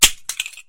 Звуки мышеловки
Звук мишоловки — 1 варіант